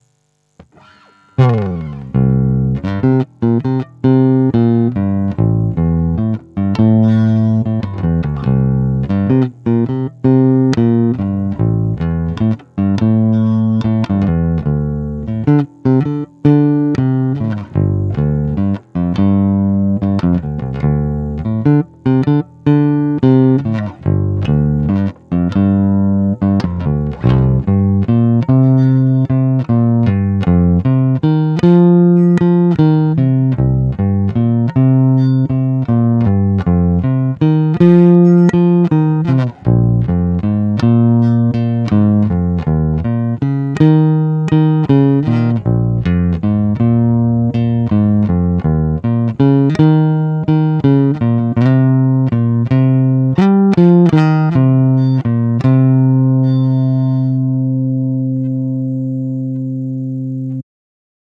No asi klasický jazz bass J Posuďte sami z nahrávek, které jsou provedeny rovnou do zvukovky bez úprav.
Krkový a tonovka na plno